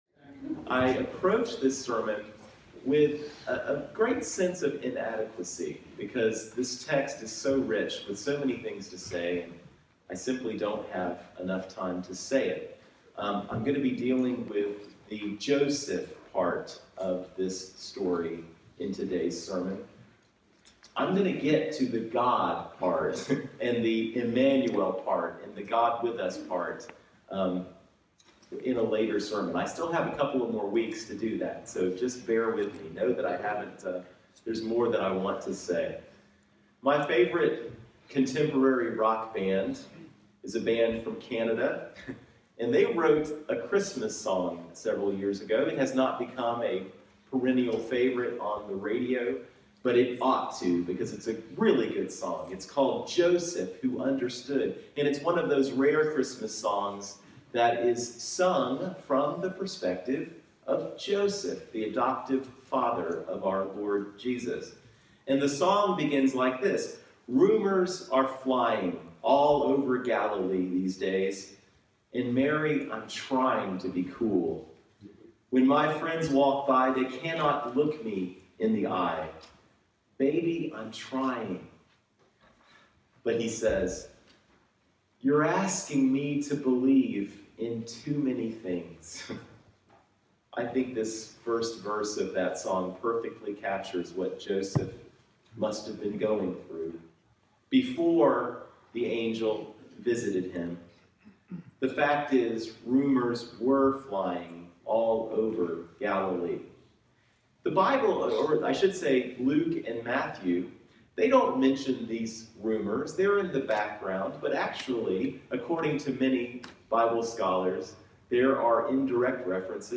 Sermon Text: Matthew 1:18-25